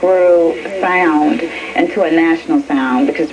DJ SCREW INTERVIEW (NATIONAL).wav